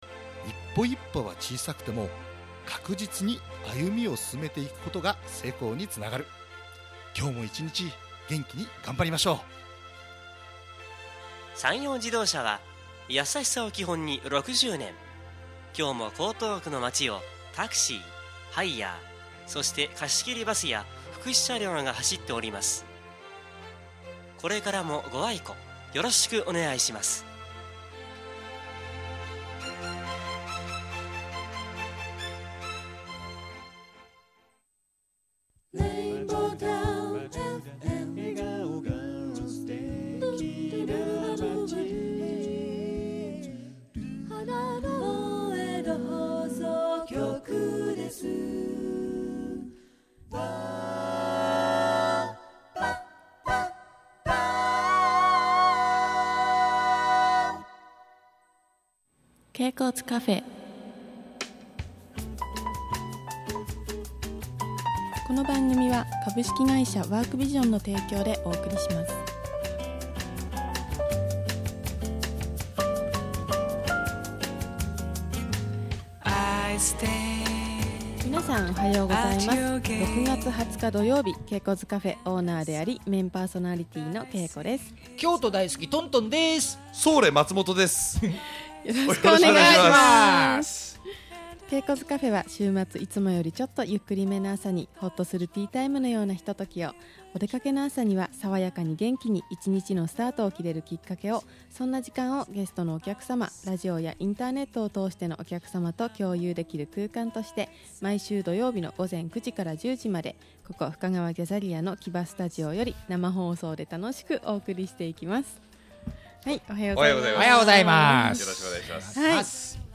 （放送を聴いていただければ分かりますが、なんだか深夜放送のようなあやしい雰囲気。。。） そして茶畑オーナー制度のことや、おぶぶの未来についても、1時間近くにわたり、お話させていただきました！